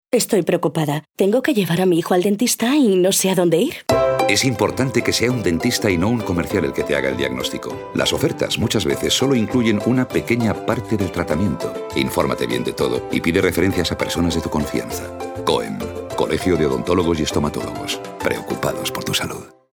Esta campaña en radio pretende advertir a la población de la importancia de exigir una atención bucodental profesional y ahonda en el mensaje de que “es importante que sea un dentista, y no un comercial, el que te haga el diagnóstico”.
Esta iniciativa de ámbito regional, se emitió durante los meses de enero, febrero y marzo de 2018 en los horarios de máxima audiencia de las cadenas de radio generalista: Ser, Cope y Onda Cero.